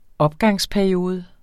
Udtale [ ˈʌbgɑŋs- ]